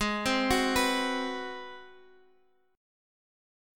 Ab7#9 Chord
Listen to Ab7#9 strummed